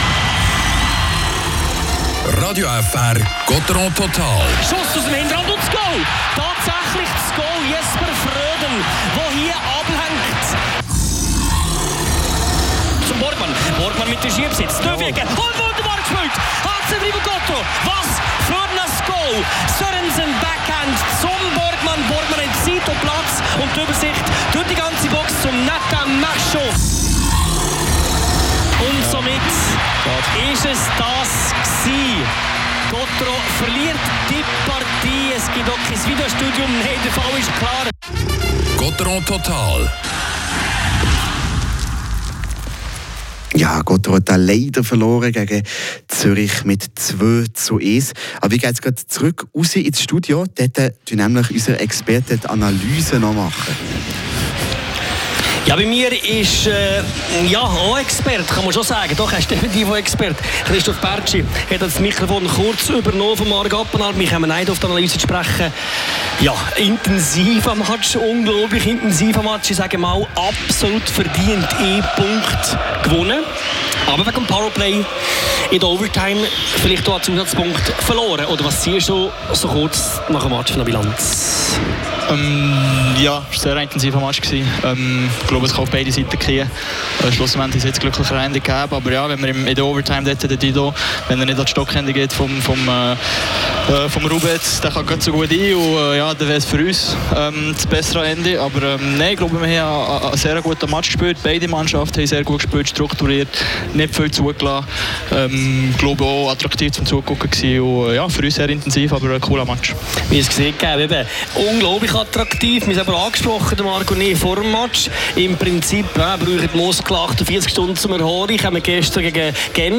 Interview mit den Spielern Christoph Bertschy und Samuel Walser.